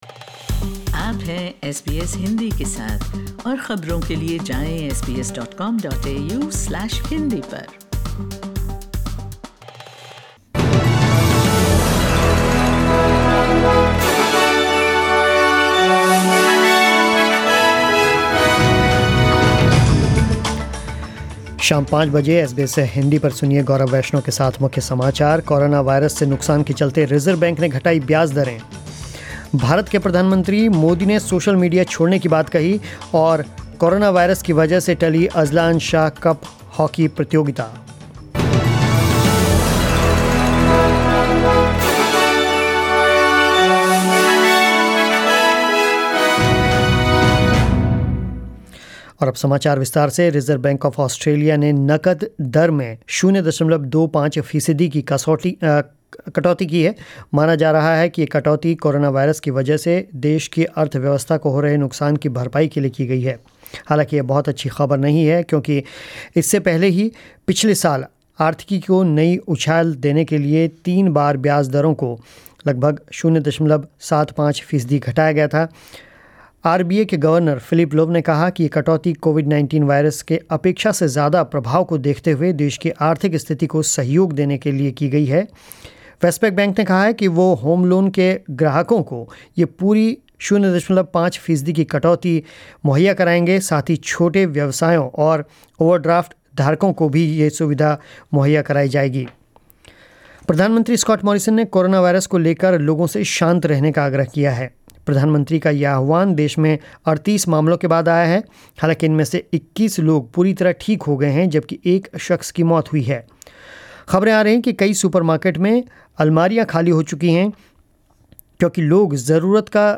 News in Hindi 03 March 2020